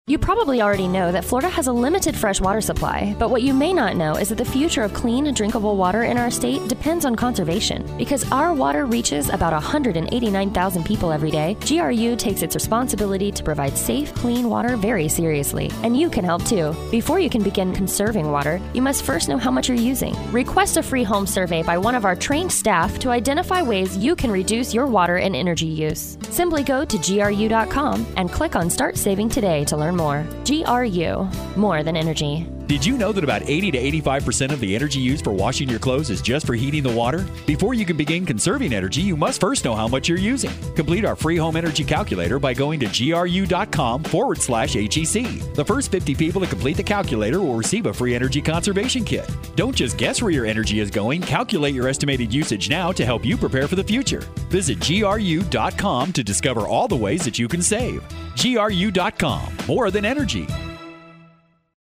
Length Radio Spot   Length Radio Spot